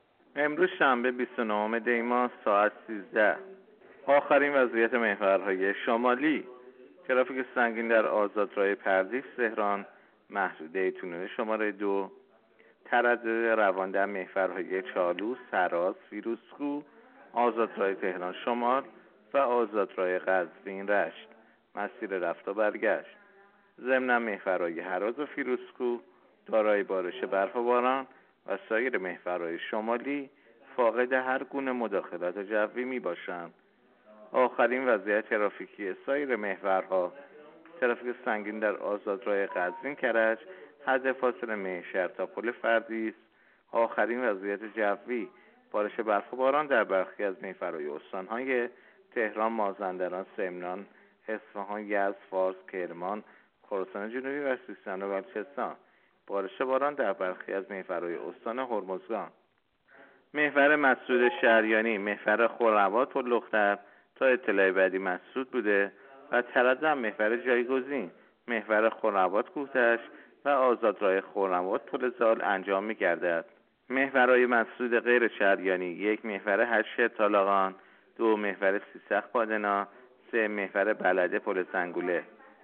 گزارش رادیو اینترنتی از آخرین وضعیت ترافیکی جاده‌ها تا ساعت ۱۳ بیست و نهم دی؛